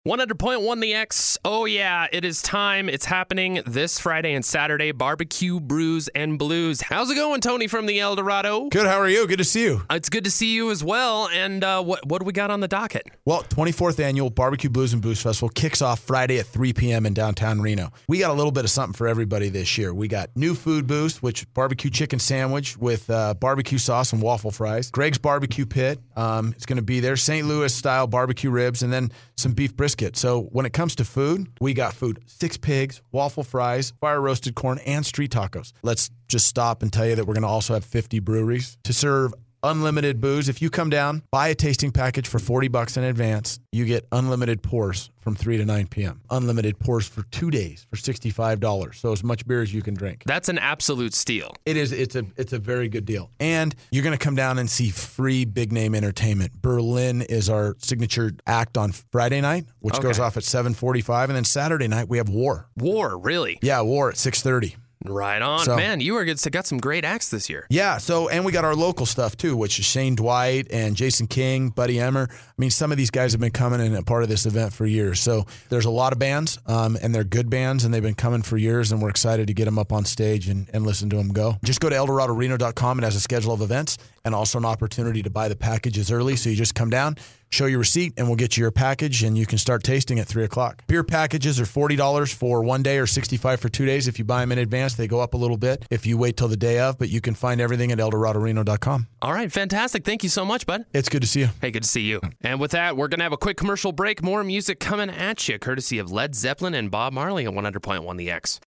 Interview: The Great Eldorado BBQ, Blues and Brews Festival